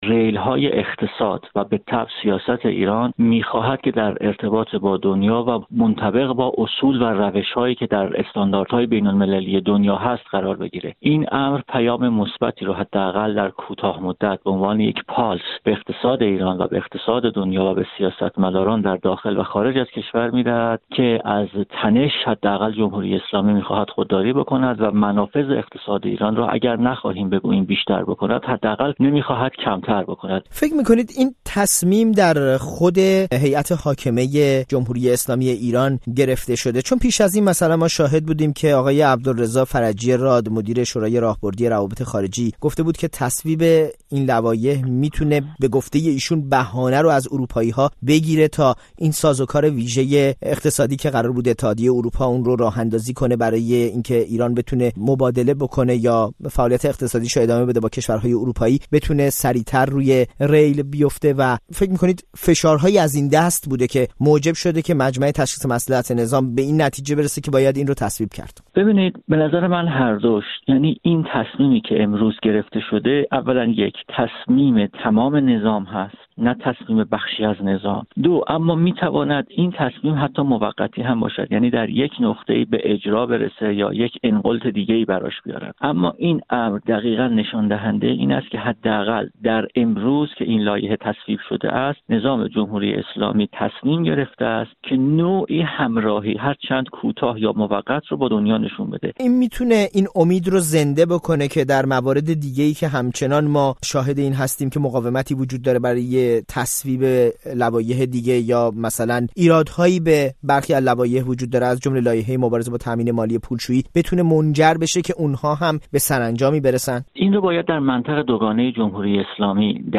برنامه‌های رادیویی